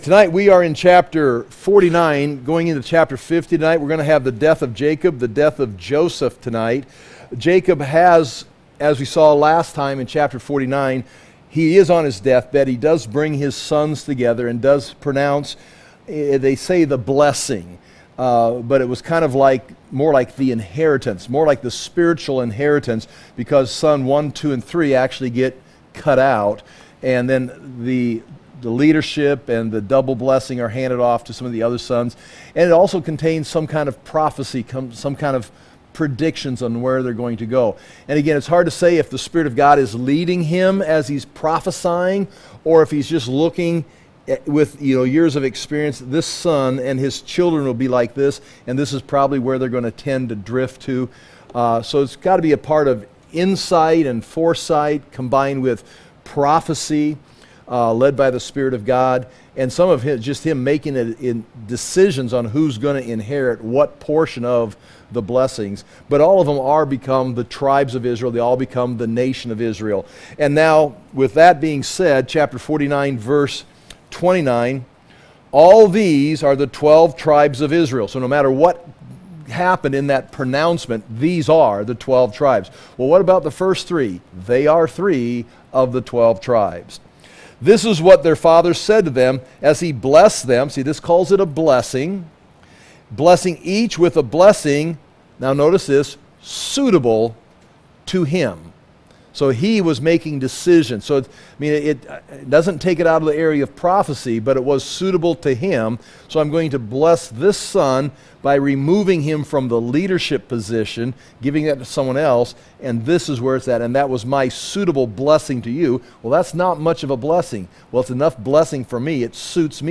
Verse by verse teaching through the book of Genesis.